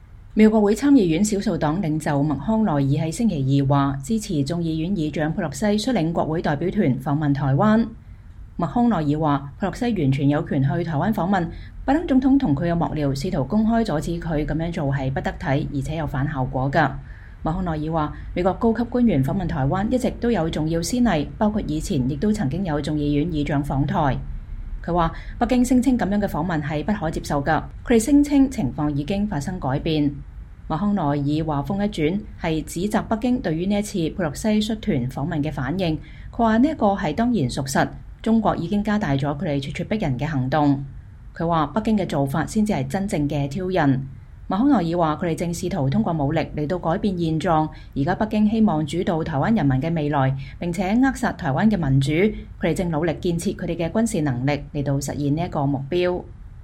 “她（佩洛西）完全有權去（台灣訪問），拜登總統和他的幕僚試圖公開阻止她這麼做是不得體的，且只有反效果，”麥康奈爾在載有佩洛西等國會議員訪團的飛機抵達台北後，在參議院院會發言說。